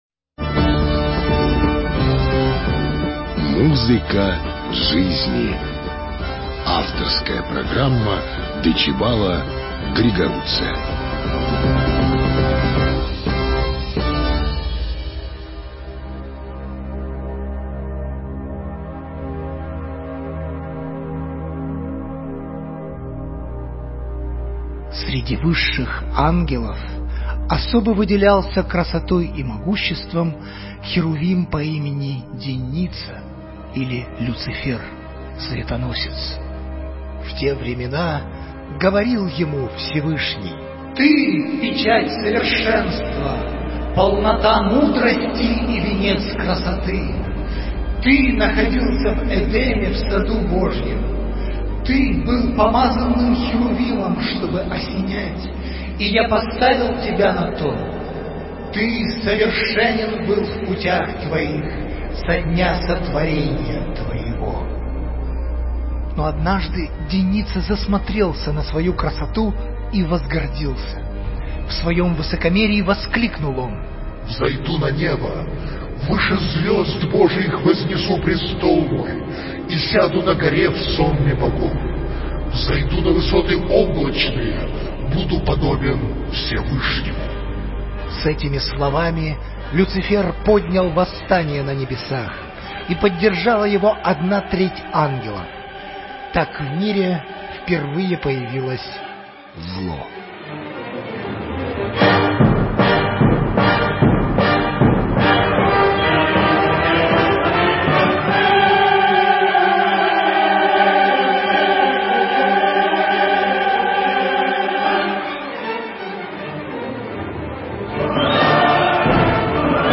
Познавательно-просветительская авторская передача музыковеда